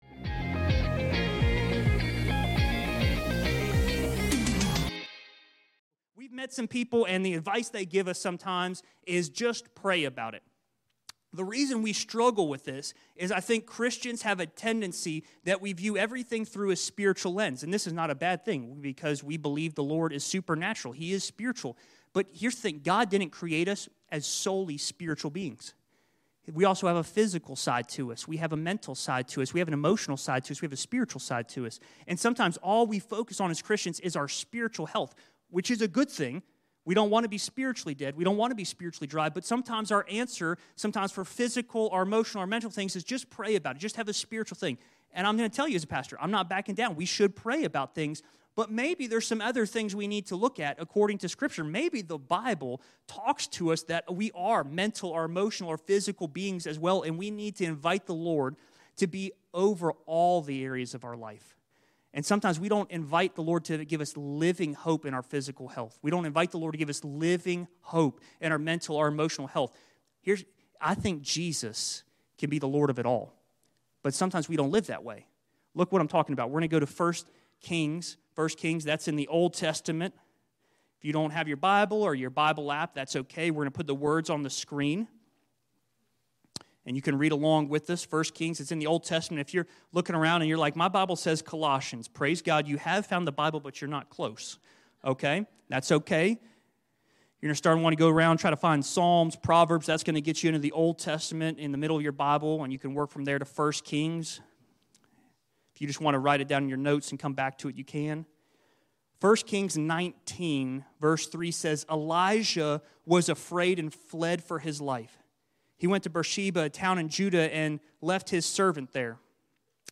In this sermon series we’re discussing the importance of having Living Hope in all areas of life. This week we talked about keeping our focus on Jesus in regard to physical health.